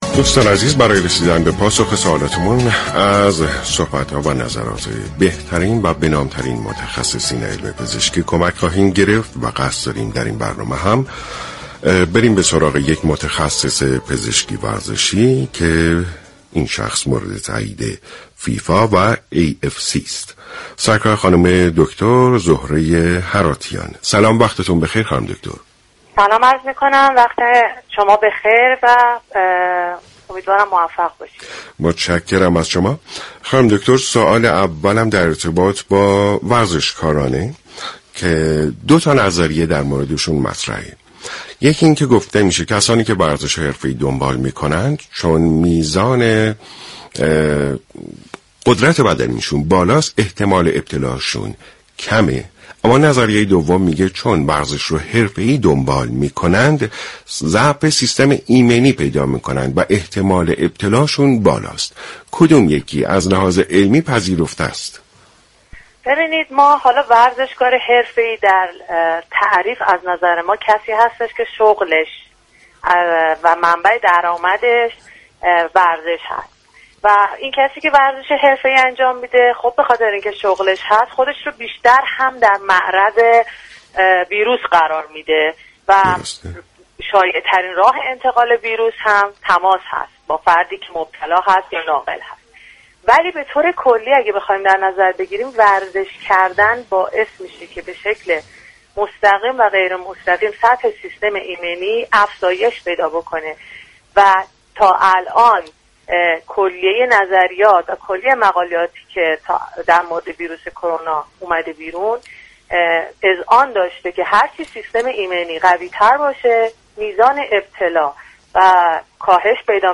شما می توانید از طریق فایل صوتی ذیل شنونده بخشی از برنامه سلامت باشیم رادیو ورزش كه شامل صحبت های این متخصص پزشكی ورزشی درباره كرونا است؛ باشید.